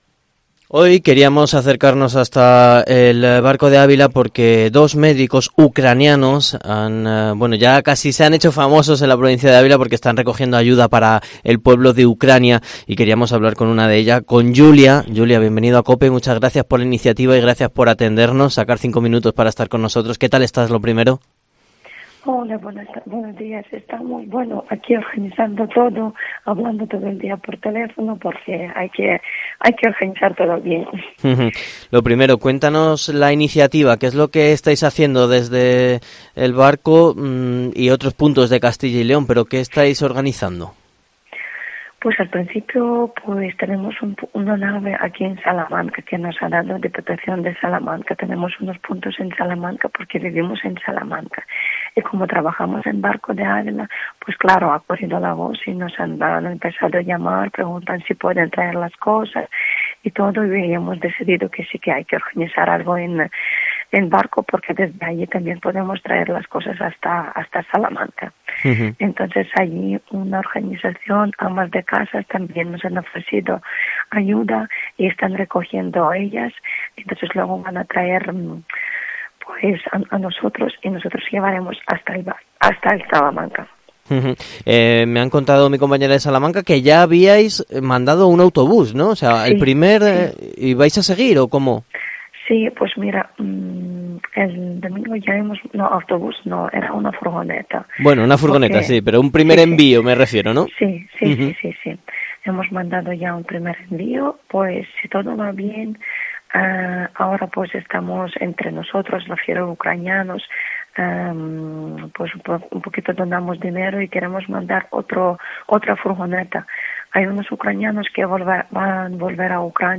AUDIO: Entrevista El Barco de Ávila -ucrania